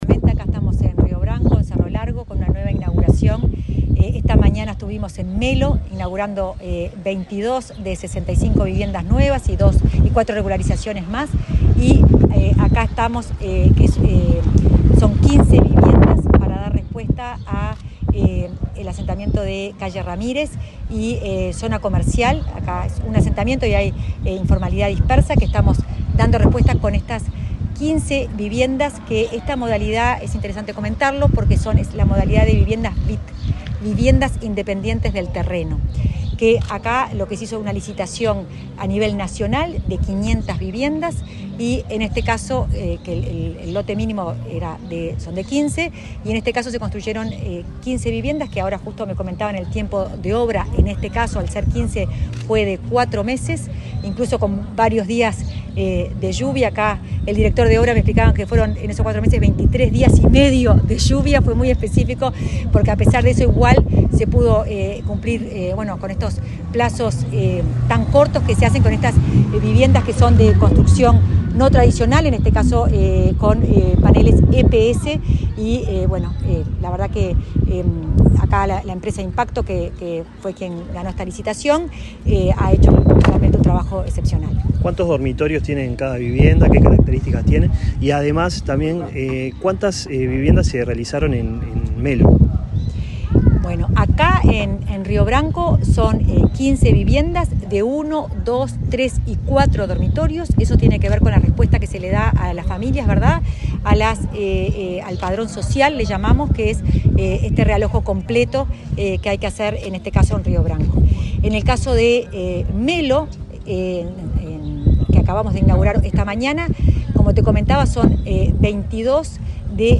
Entrevista a la directora nacional de Integración Social y Urbana, Florencia Arbeleche
Entrevista a la directora nacional de Integración Social y Urbana, Florencia Arbeleche 18/11/2024 Compartir Facebook X Copiar enlace WhatsApp LinkedIn La directora nacional de Integración Social y Urbana del Ministerio de Vivienda, Florencia Arbeleche, dialogó con Comunicación Presidencial, antes de inaugurar viviendas del Plan Avanzar, en la localidad de Río Branco, departamento de Cerro Largo.